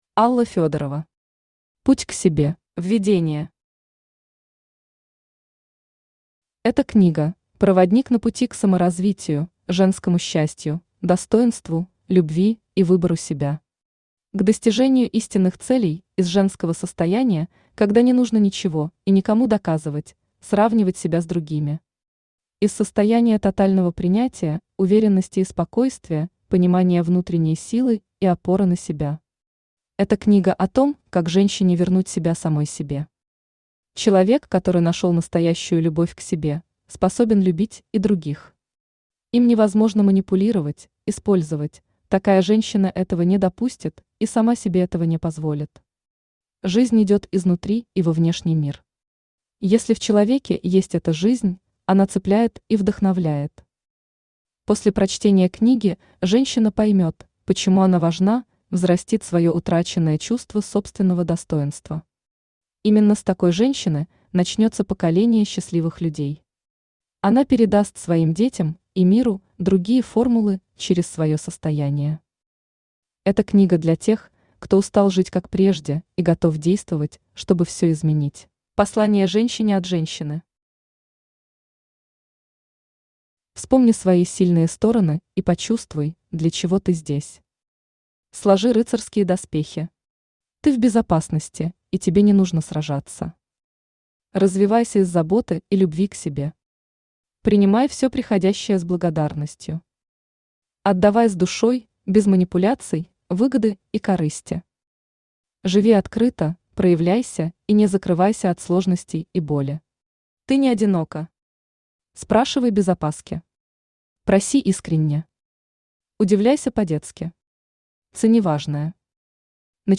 Аудиокнига Путь к себе | Библиотека аудиокниг
Aудиокнига Путь к себе Автор Алла Фёдорова Читает аудиокнигу Авточтец ЛитРес.